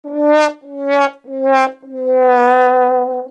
Другие рингтоны по запросу: | Теги: тромбон, Trombone
Категория: Рингтон на SMS